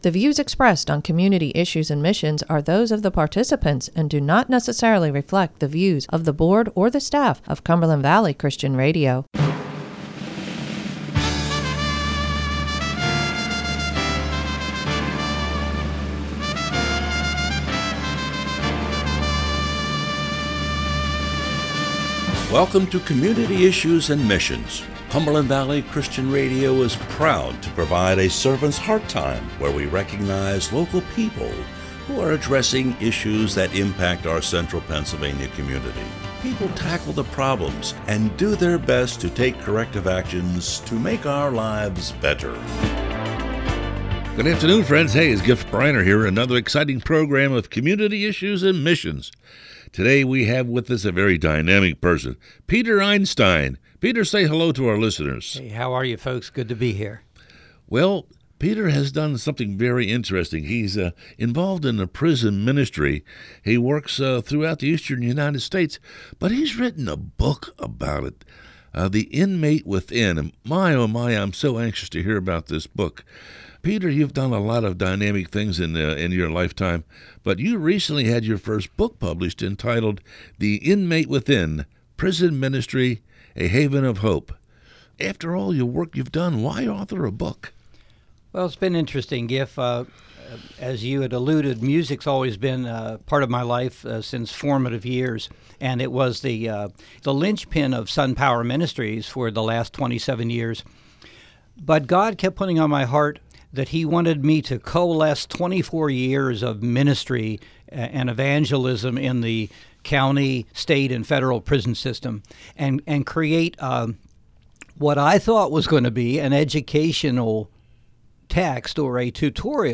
WPFG's Community Issues and Missions is a program where people in our community share their personal journey, their ministry, or their organization.